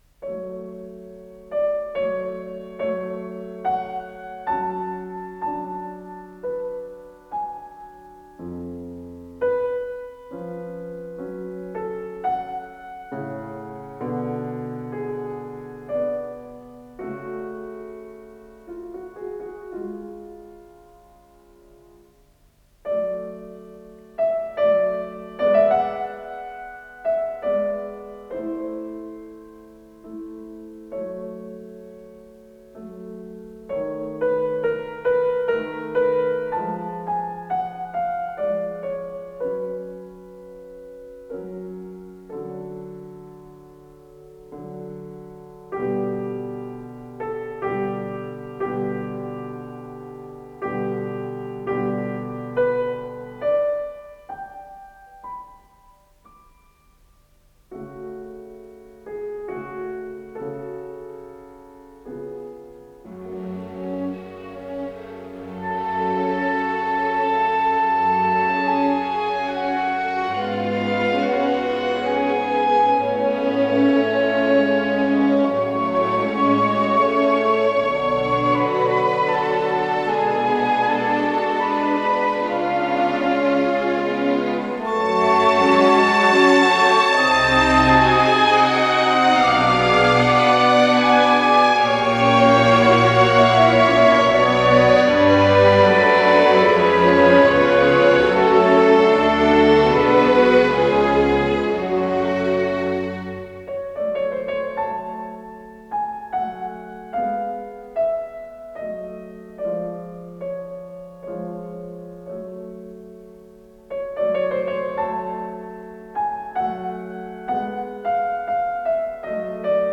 с профессиональной магнитной ленты
К-488 - ля мажор
Адажио
ИсполнителиАртур Лима - фортепиано (Бразилия)
АккомпаниментМосковский камерный оркестр
Художественный руководитель и дирижёр - Р. Баршай
ВариантДубль моно